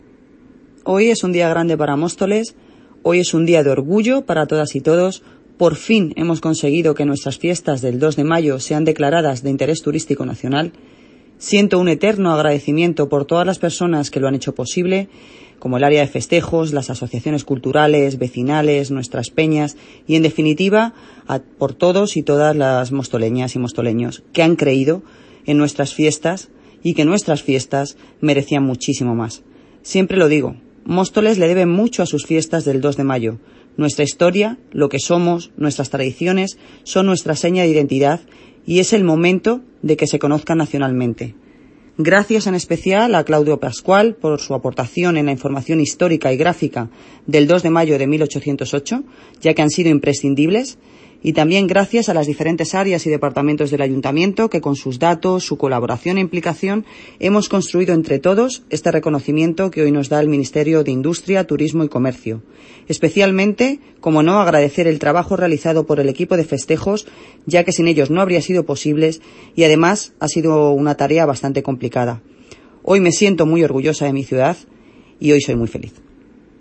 Audio - Noelia Posse (Alcaldesa de Móstoles) Sobre Fiestas del 2 de Mayo